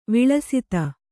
♪ viḷasita